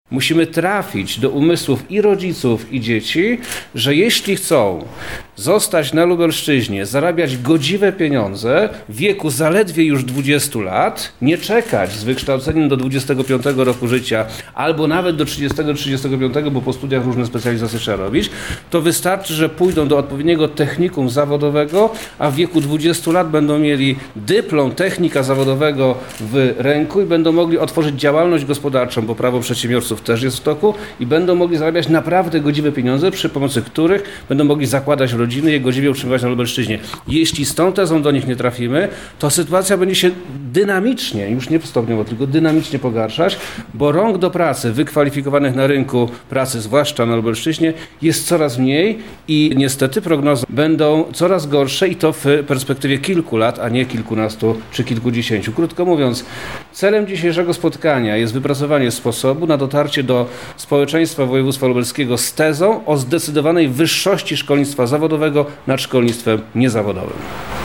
„Koniecznością jest jak najszybsze wypromowanie tezy o absolutnej wyższości kształcenia zawodowego, nad niezawodowym” – mówi Przemysław Czarnek